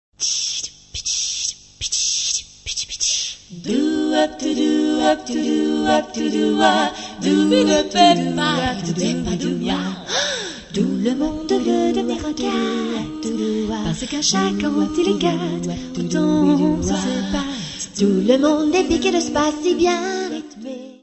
voz.
: stereo; 12 cm
Área:  Pop / Rock